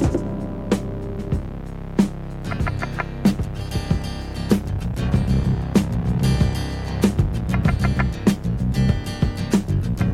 • 95 Bpm '70s Pop Drum Groove C Key.wav
Free drum groove - kick tuned to the C note. Loudest frequency: 719Hz
95-bpm-70s-pop-drum-groove-c-key-Jj8.wav